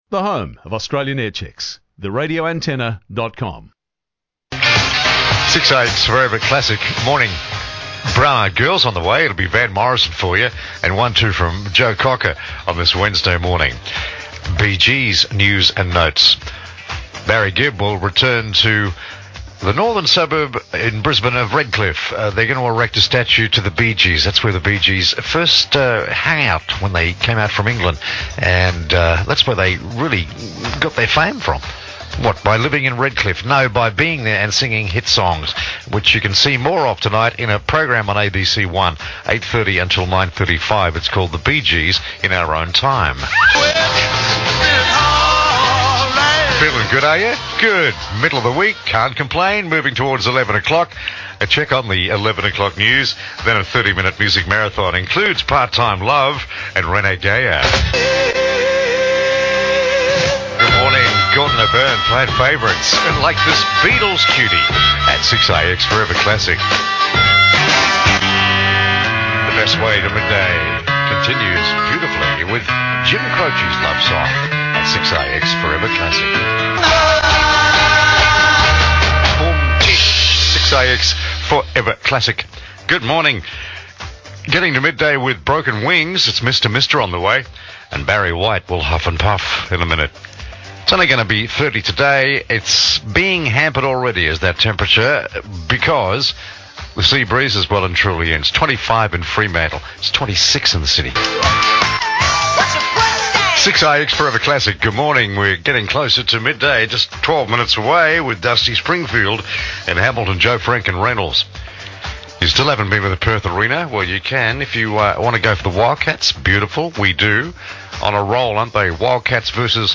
One of Australia’s top old school jocks he’s still showin’ em’ how it’s done